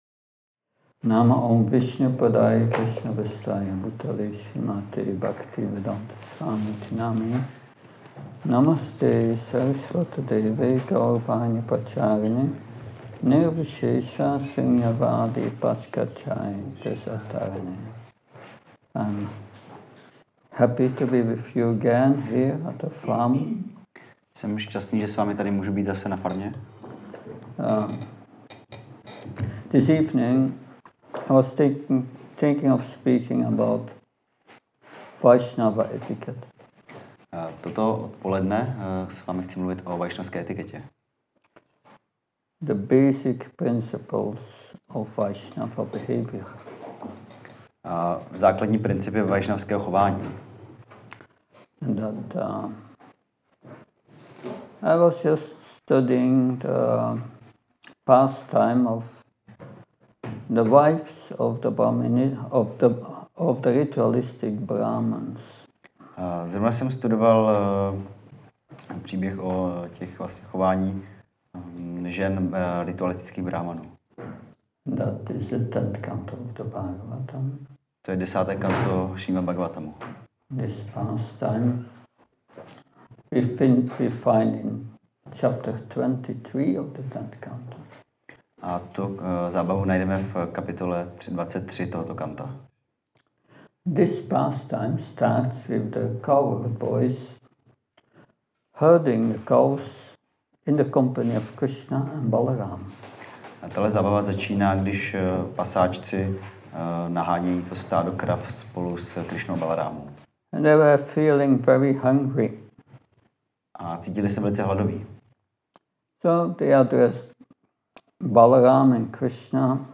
Přednáška Vaišnavská etiketa – Krišnův dvůr